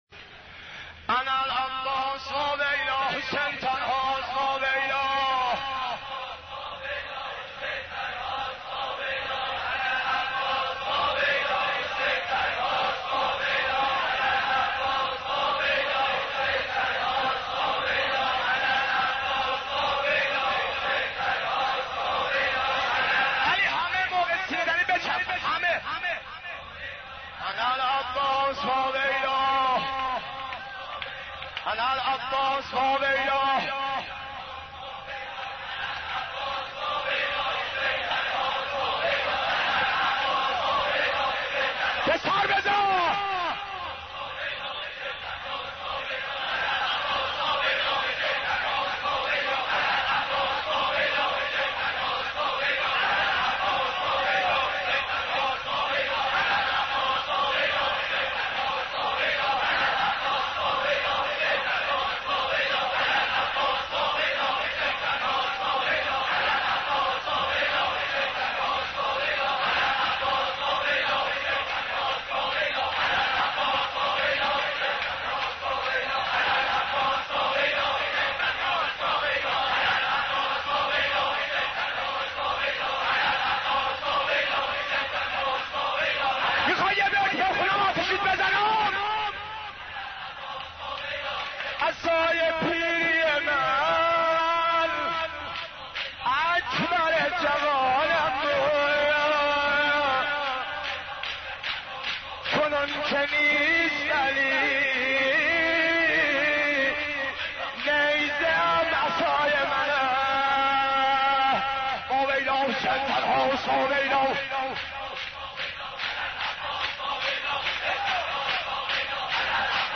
حضرت عباس ع ـ شور 21